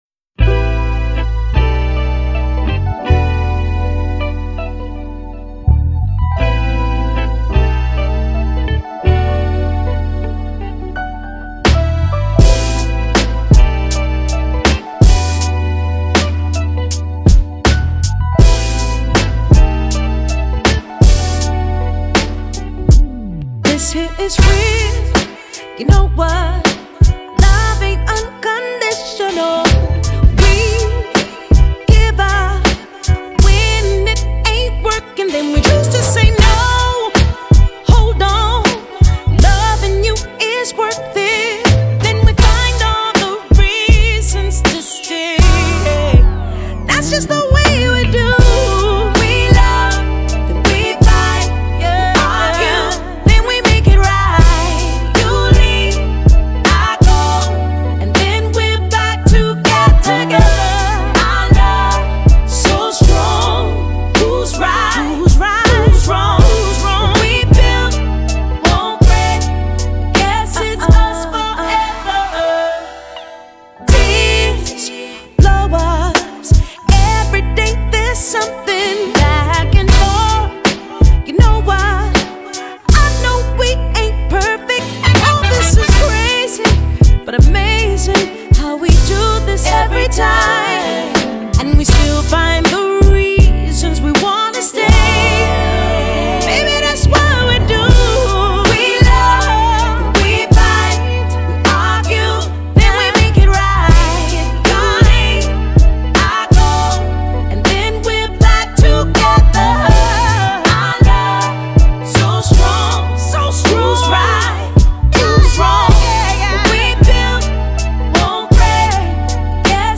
The vocals on this album are amazing.